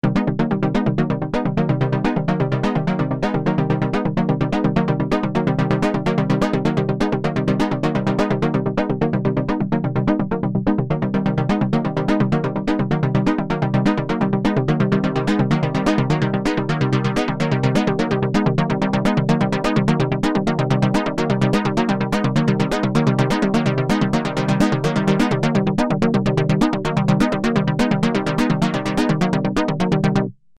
RS6 – Detroit Stab | Supercritical Synthesizers
RS6-Detroit-Stab.mp3